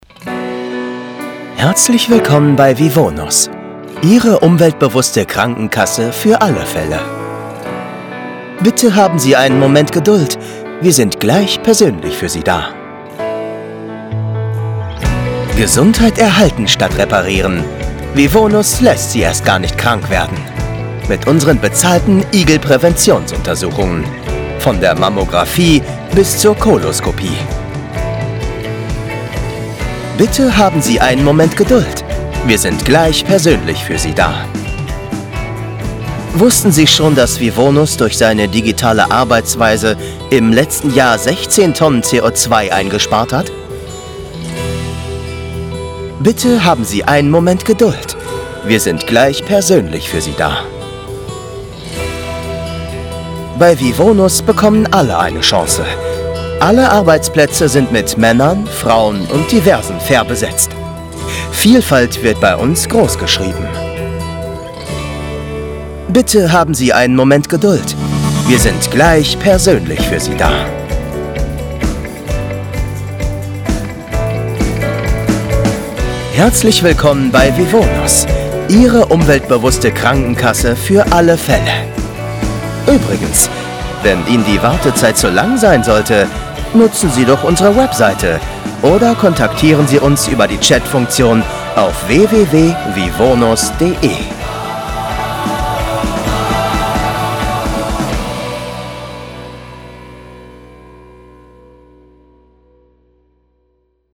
Medical Insurance Hold Message
Young Adult
Acoustics: Vocal booth including Caruso Iso Bond 10cm, Basotect for acoustic quality.